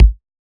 99Sounds x Monosounds - Kick - 001 - G#.wav